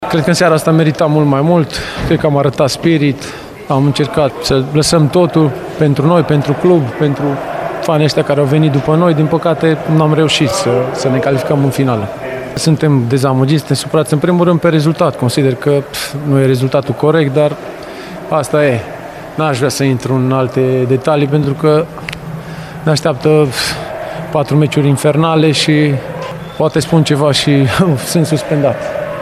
Doi dintre jucătorii cu experiență – Paul Anton și Alexandru Benga – au îndreptat ”săgeți” către arbitrul bucureștean: